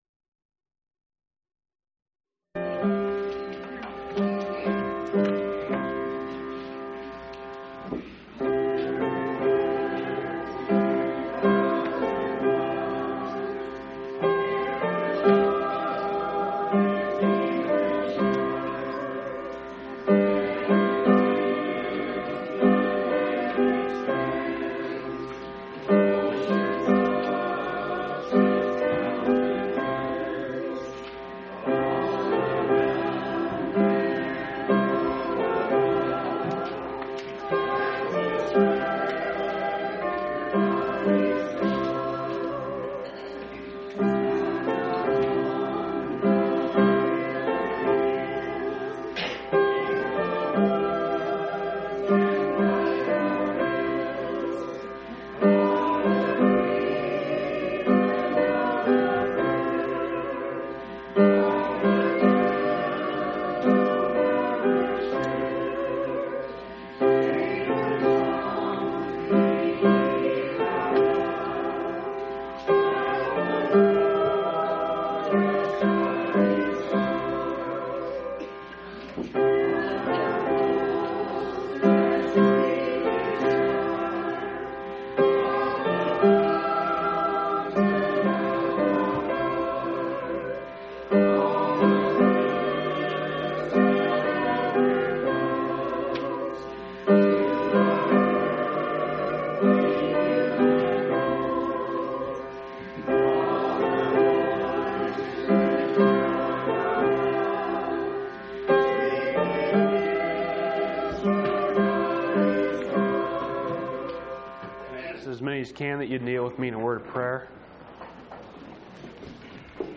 3/14/1993 Location: Phoenix Local Event